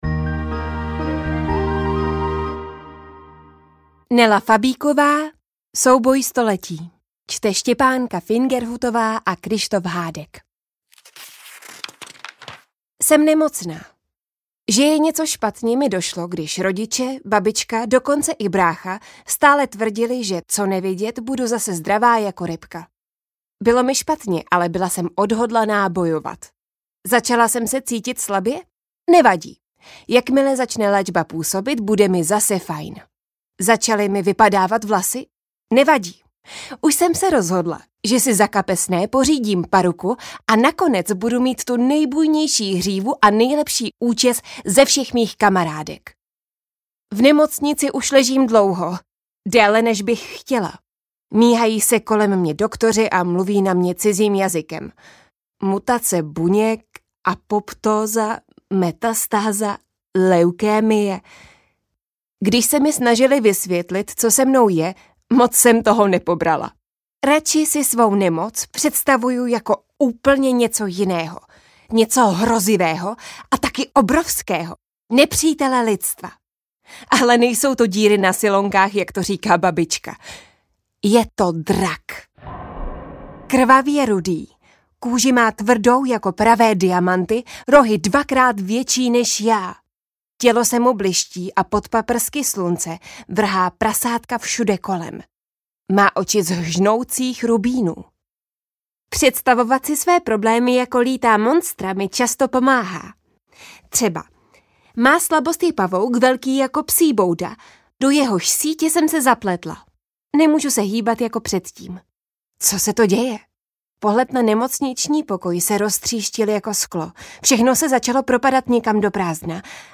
Mou povídku namluvila Štěpánka Fingerhutová a Kryštof Hádek.